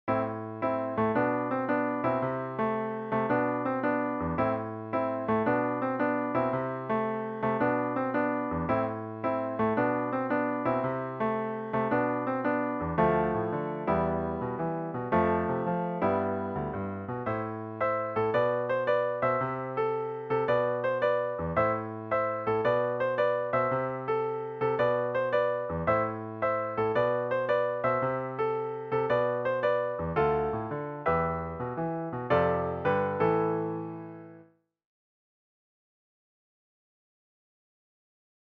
The chords for the excerpt are: Am – G/B – Am/C – G/B. The chords for the 4th phrase are: Dm – Em – F – Em.
2. Change the octave (and do other instrumental changes). For this example, I’ve gone back to the first version, but simply changed the second run-through to be an octave higher.